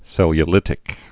(sĕlyə-lō-lĭtĭk)